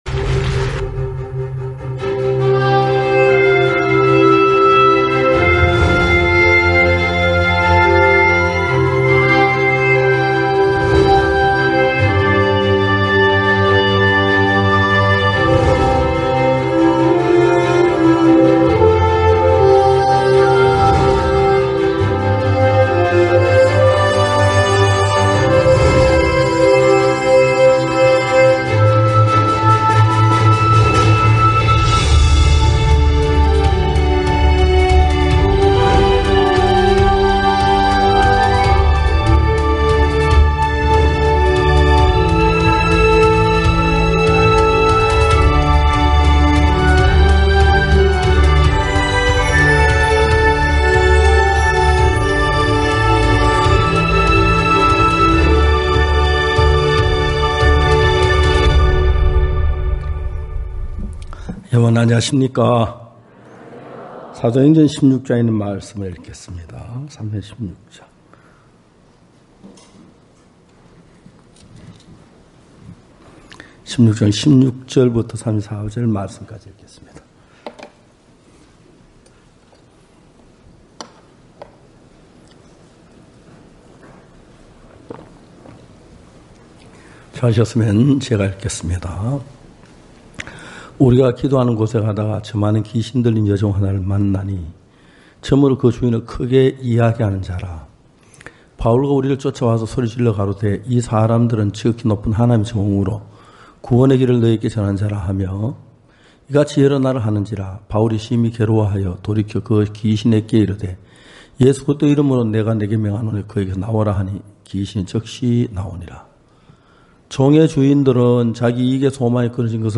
고난을 축복으로 바꾸시는 하나님 | 서울지역 연합예배
전국 각 지역의 성도들이 모여 함께 말씀을 듣고 교제를 나누는 연합예배.